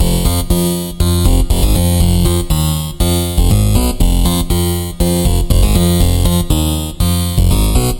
Tag: 120 bpm Electro Loops Synth Loops 1.35 MB wav Key : Unknown